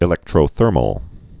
(ĭ-lĕktrō-thûrməl)